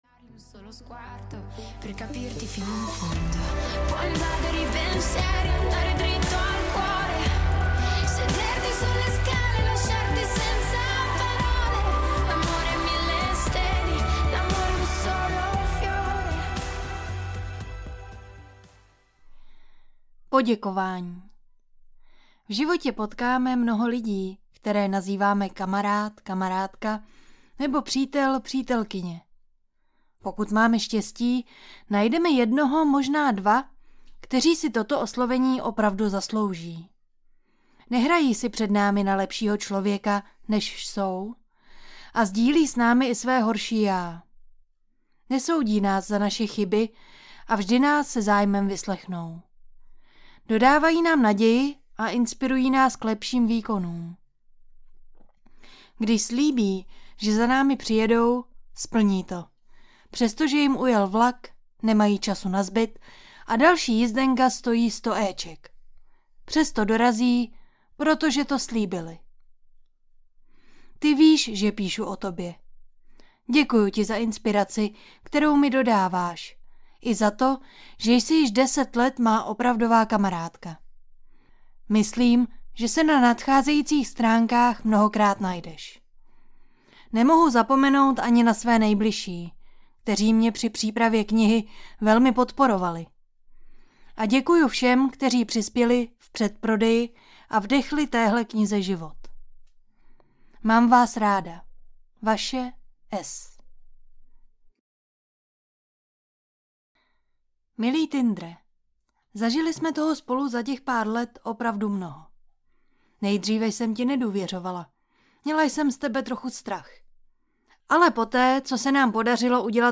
Ukázka z knihy
prezila-jsem-tinder-audiokniha